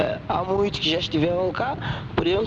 SpokenPortugueseGeographicalSocialVarieties_splits
Automatic Speech Recognition